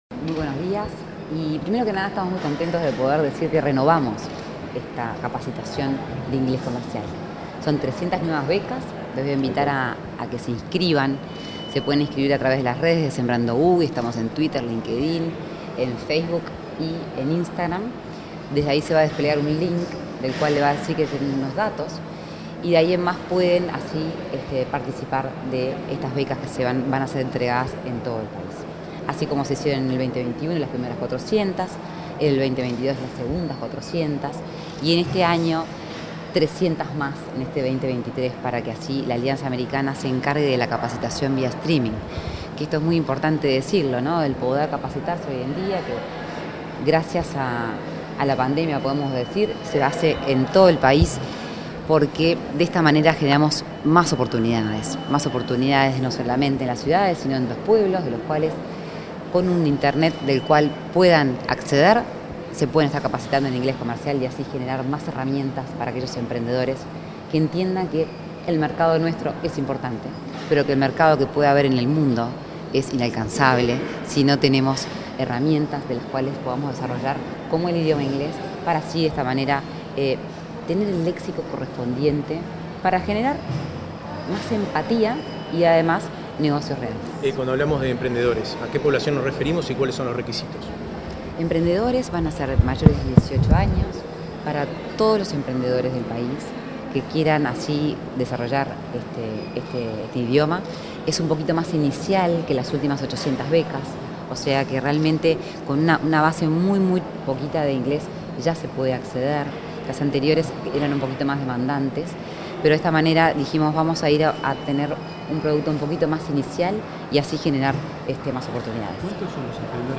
Declaraciones de la impulsora de Sembrando, Lorena Ponce de León 28/03/2023 Compartir Facebook X Copiar enlace WhatsApp LinkedIn Tras el lanzamiento de la tercera edición de Sembrando Inglés Comercial, este 28 de marzo, la impulsora del programa, Lorena Ponce de León, realizó declaraciones a la prensa.